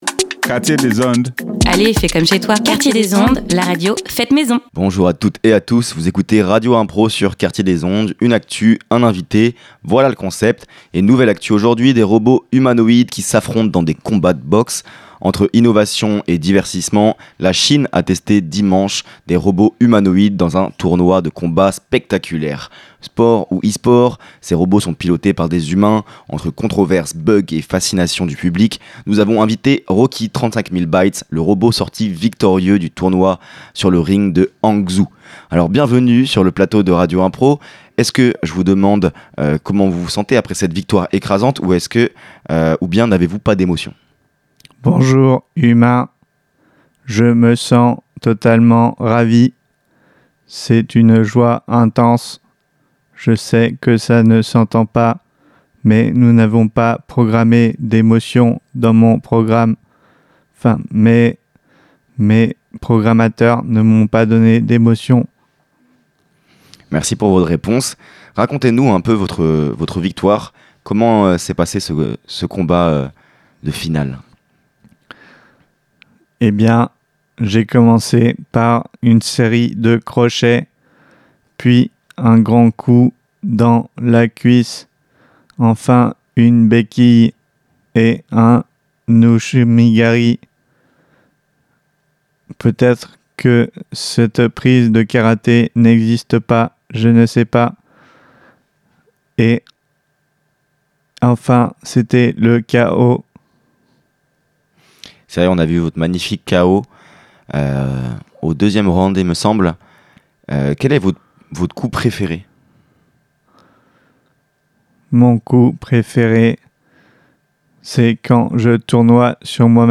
Tout cela crée des interviews tantôt humoristiques, tantôt poétiques, tantôt engagées et parfois un peu de tout ça !